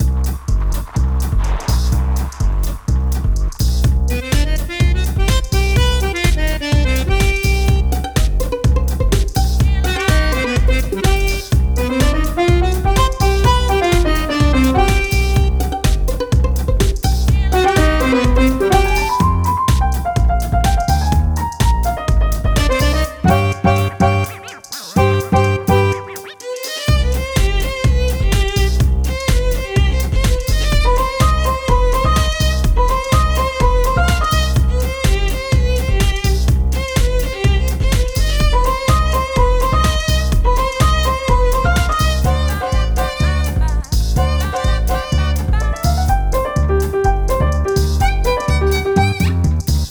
【イメージ】エレクトロ・スウィング など